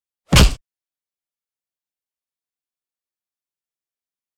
赤手空拳击中肉体12-YS070524.mp3
通用动作/01人物/03武术动作类/空拳打斗/赤手空拳击中肉体12-YS070524.mp3